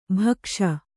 ♪ bhakṣa